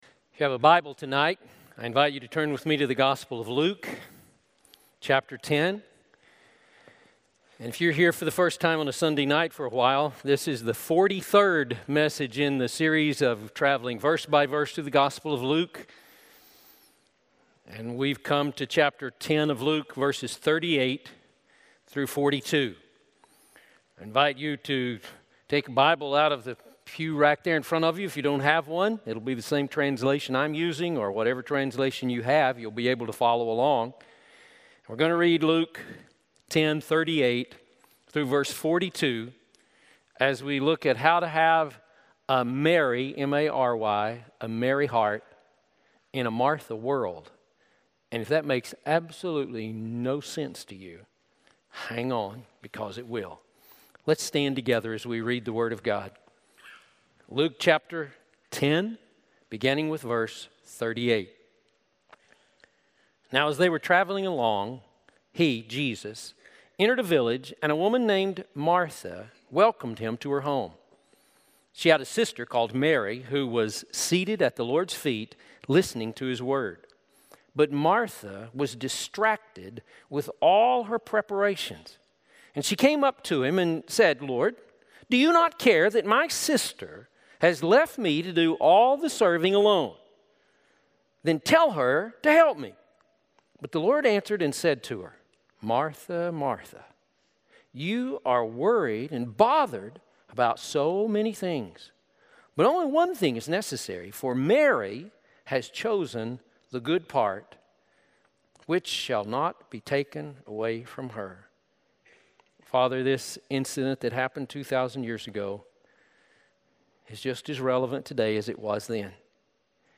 A message from the series "The Gospel of Luke."